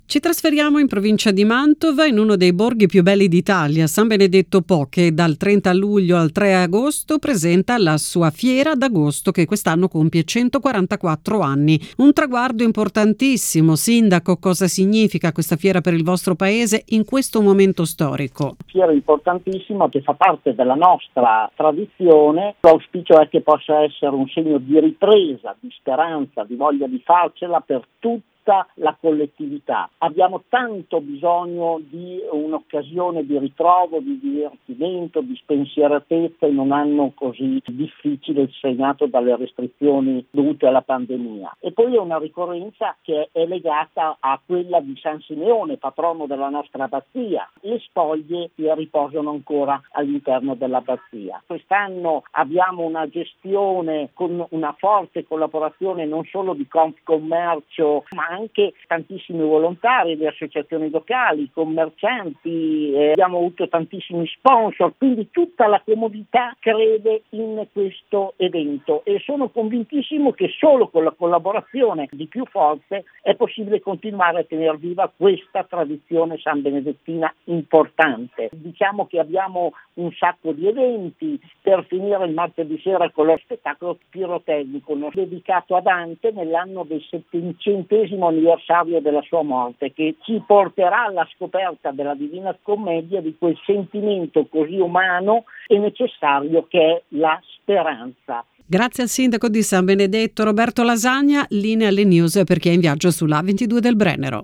28/07/2021: intervista al Sindaco di San Benedetto Po, in provincia di Mantova, Roberto Lasagna, per la 144ma edizione della Fiera di Agosto: